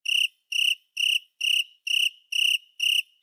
insectnight_8.ogg